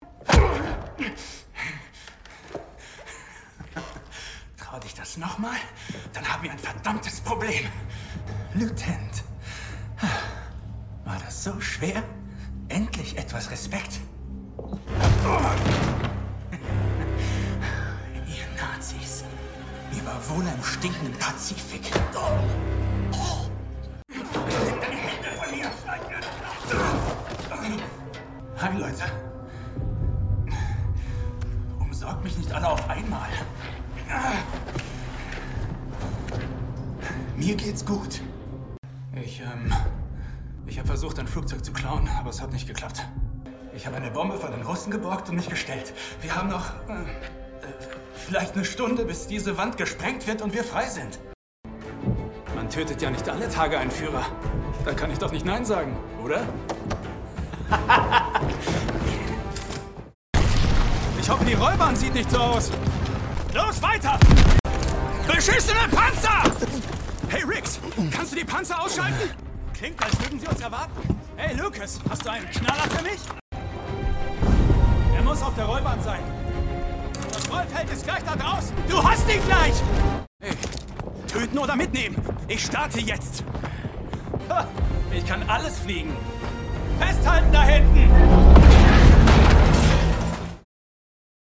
Rolle „Wade Jackson“ – cool, selbstbewusst